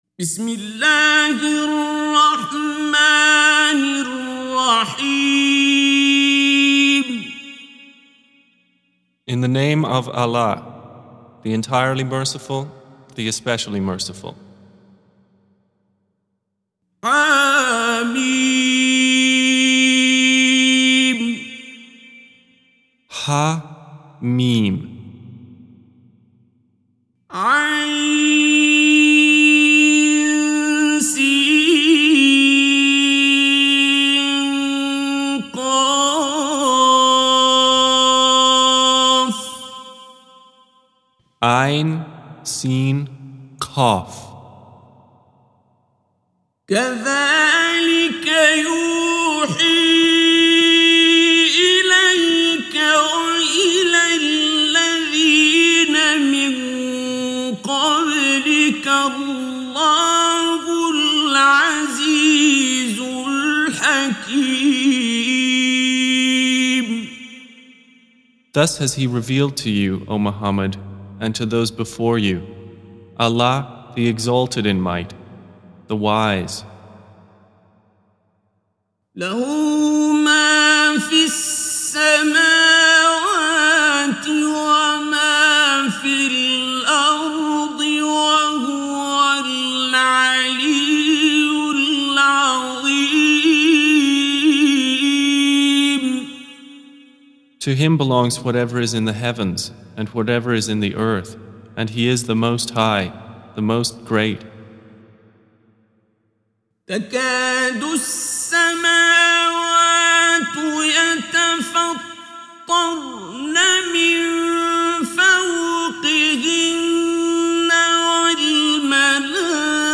Audio Quran Tarjuman Translation Recitation